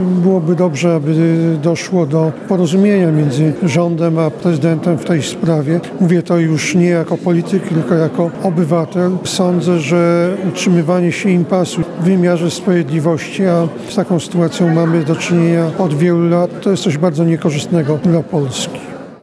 Jarosław Gowin był dziś gościem oficjalnie rozpoczynającego drugą kadencję prezydenta Chełma Jakuba Banaszka.